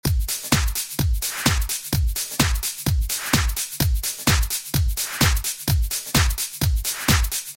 Here is a sample of the type of beat you could expect to end up with at the end of this series of tips: